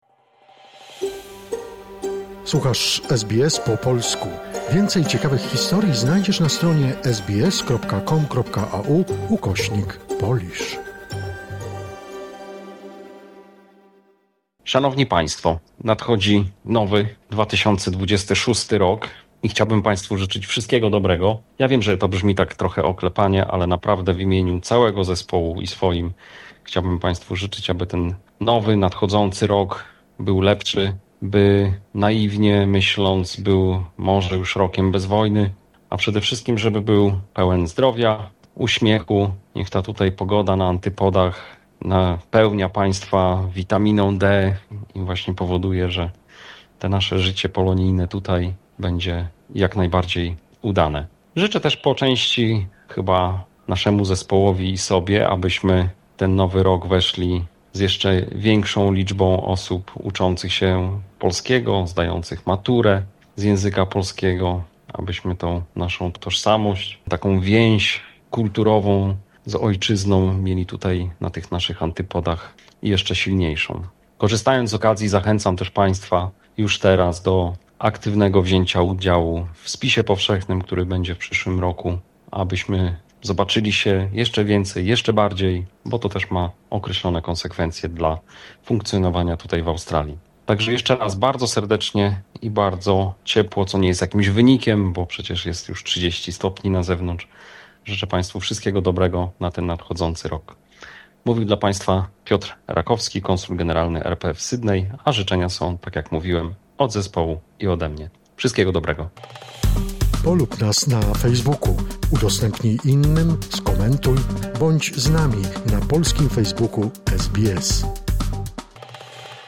Z okazji Nowego 2026 Roku, życzenia składa Konsul Generalny RP w Sydney, Piotr Rakowski.